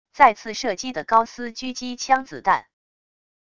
再次射击的高斯狙击枪子弹wav音频